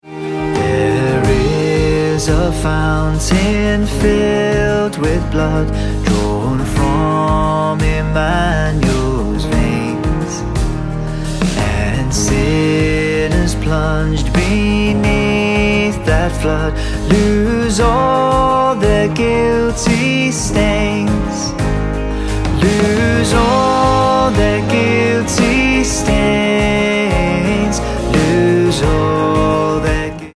Ab